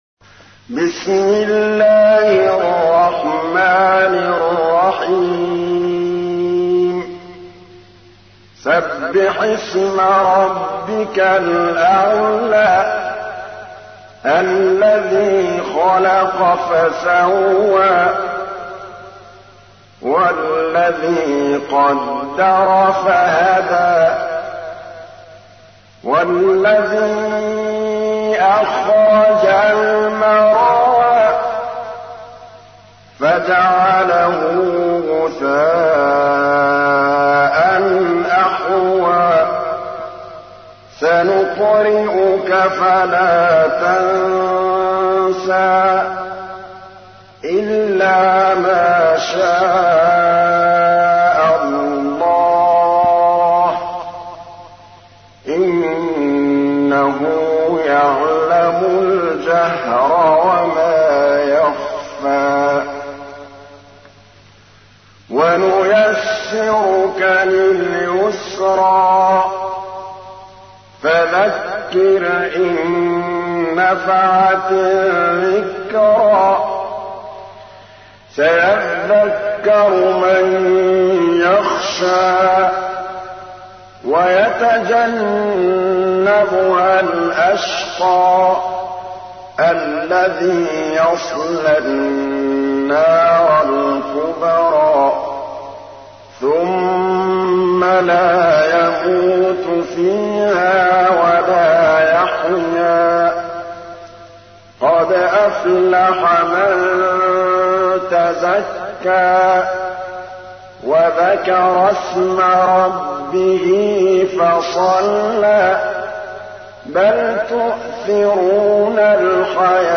تحميل : 87. سورة الأعلى / القارئ محمود الطبلاوي / القرآن الكريم / موقع يا حسين